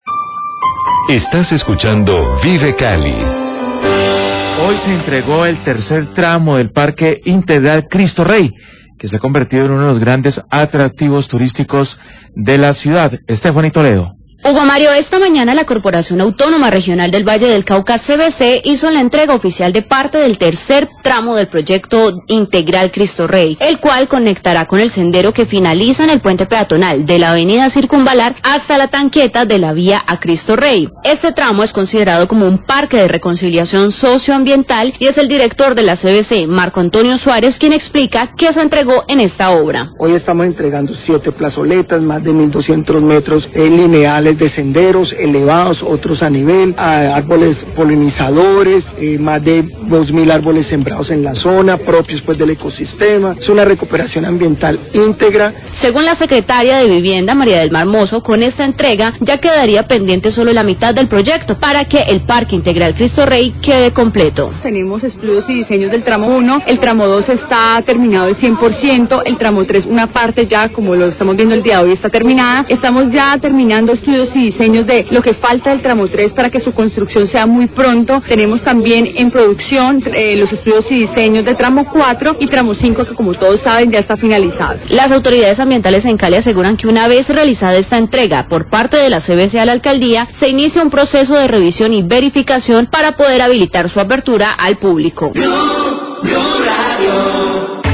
Radio
El director general de la CVC, Marco Antonio Suárez, habla de la entrega oficial a la Alcaldía de Cali, de parte del tramo III del Ecoparque de Cristo Rey. La Secretaria de Vivienda de Cali, María del Mar Mozo, hace un balance del avance de obras de los diferentes tramos de este parque.